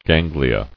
[gan·gli·a]